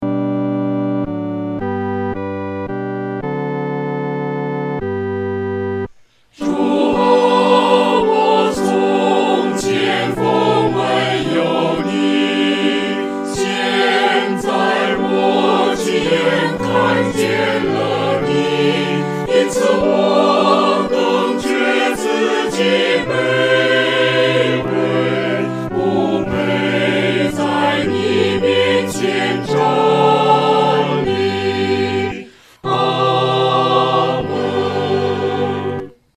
合唱
四声
指挥在带领诗班时，表情和速度应采用温柔而缓慢地。